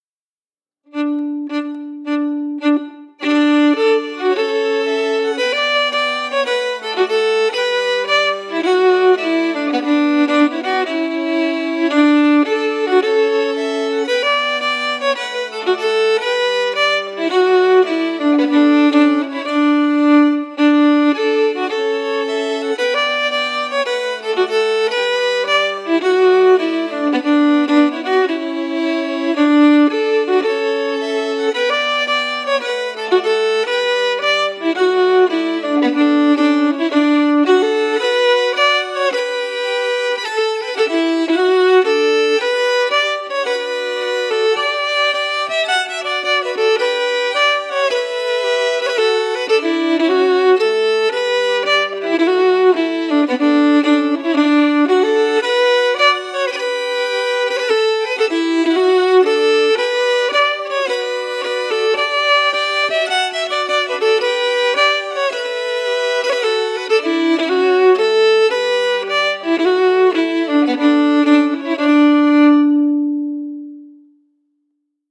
Key: D
Form: Strathspey
Melody emphasis